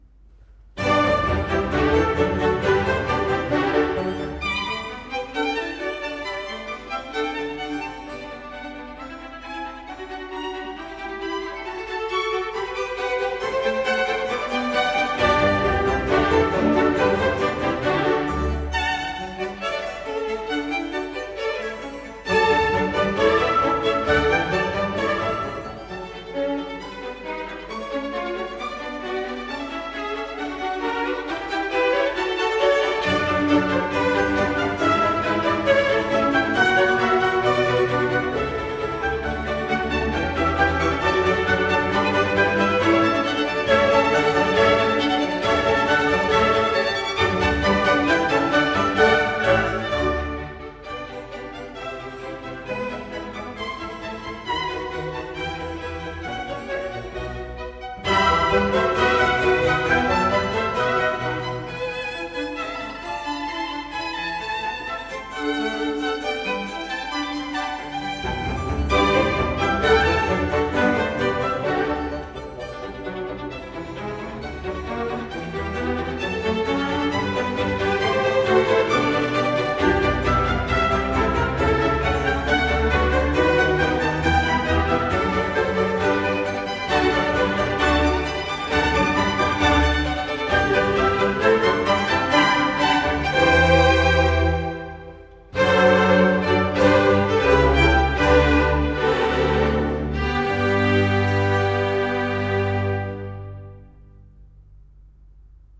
in G major - Allegro